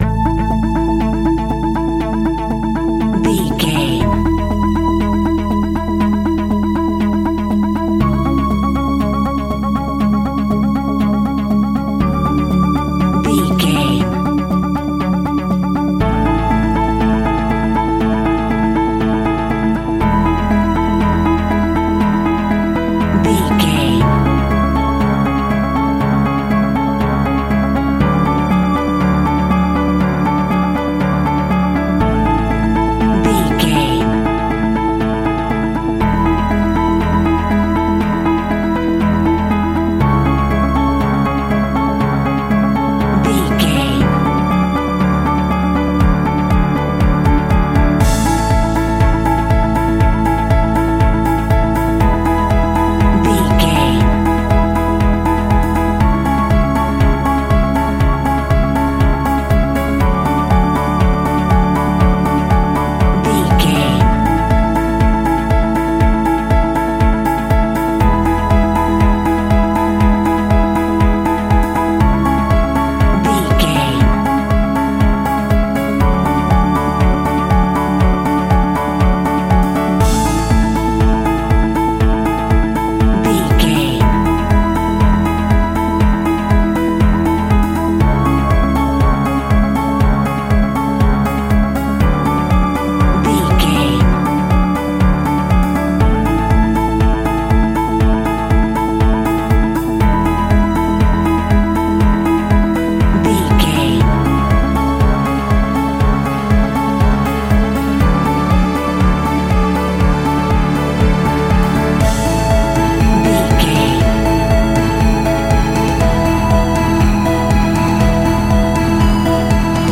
Uplifting
Ionian/Major
pop rock
indie pop
energetic
motivational
cheesy
guitars
bass
drums
piano
organ